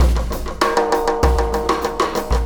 Pulsar Beat 30.wav